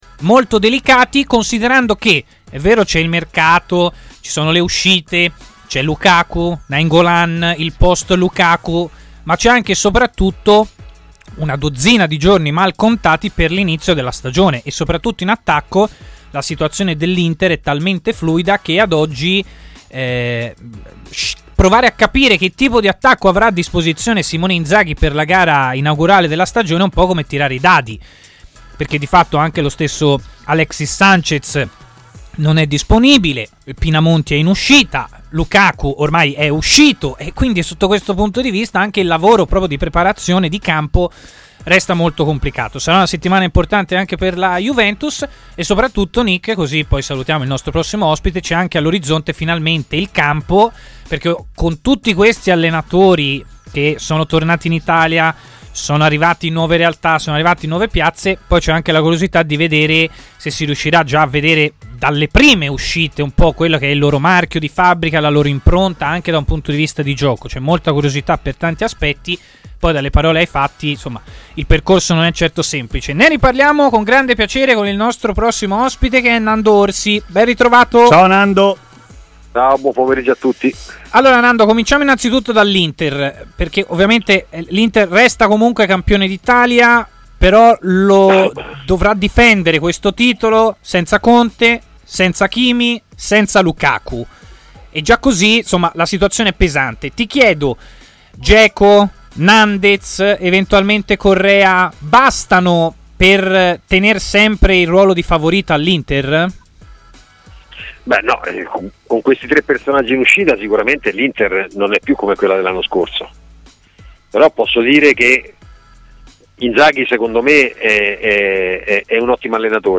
ha parlato in diretta a TMW Radio